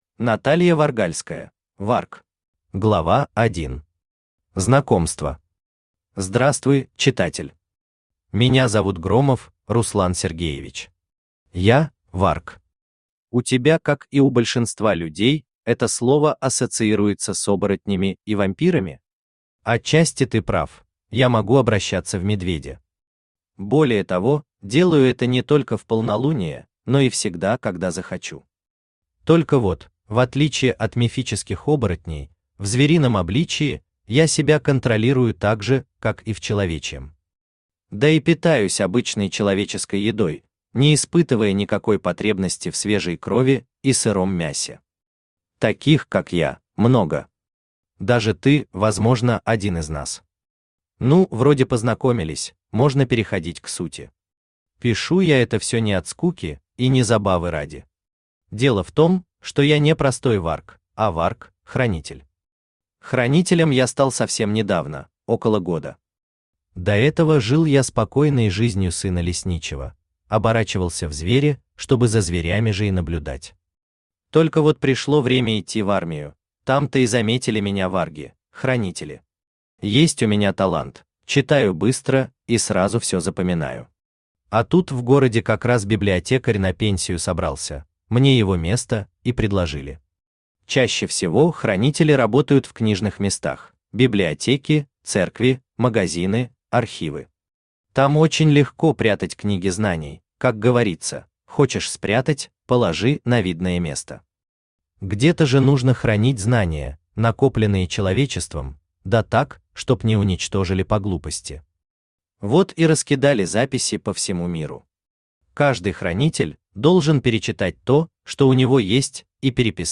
Аудиокнига Варг | Библиотека аудиокниг
Aудиокнига Варг Автор Наталья Варгальская Читает аудиокнигу Авточтец ЛитРес.